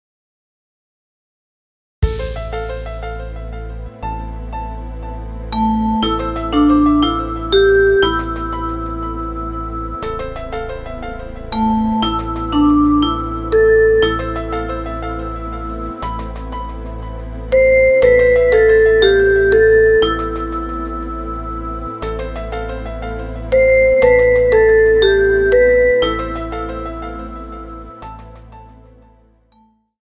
Recueil pour Violoncelle